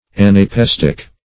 Anapestic \An`a*pes"tic\, a. [L. anapaesticus, Gr.